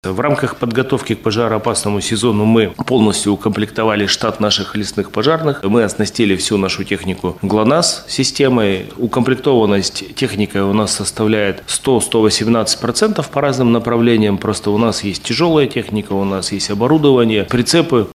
В регионе уже регистрируются лесные пожары. Всего было потушено 6 возгораний на площади 9 га. Все они возникли по вине людей,  — рассказал министр природных ресурсов и экологии Свердловской области Денис Мамонтов на пресс-конференции «ТАСС-Урал».
2204-Мамонтов.mp3